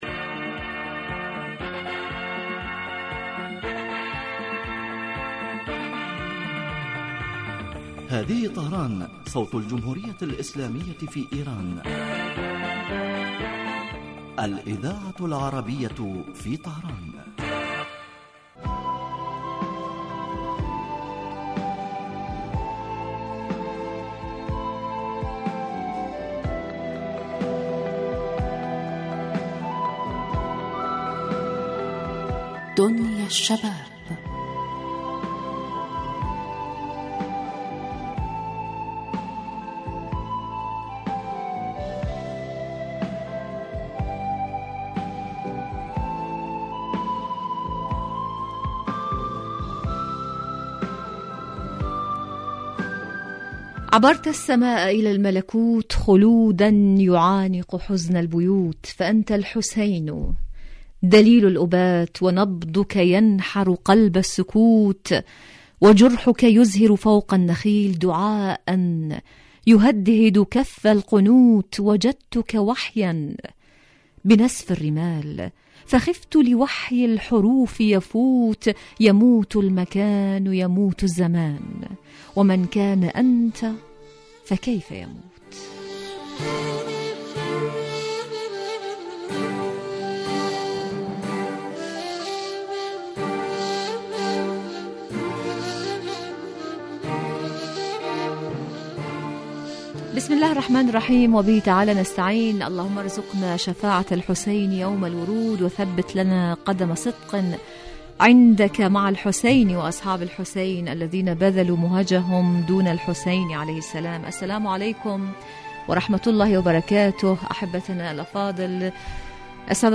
برنامج اجتماعي غني بما یستهوی الشباب من البلدان العربیة من مواضیع مجدیة و منوعة و خاصة ما یتعلق بقضایاهم الاجتماعیة وهواجسهم بالتحلیل والدراسة مباشرة علی الهواء